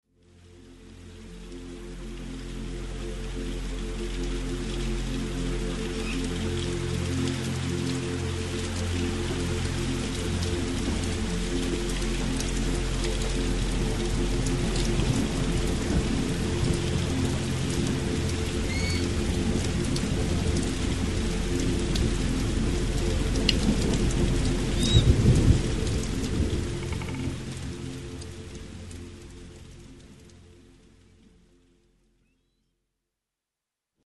Melomind vous plonge dans un paysage sonore relaxant … le chant des oiseaux de la forêt, le flux des vagues sur le rivage.
son_mer_experience_melomind-1.mp3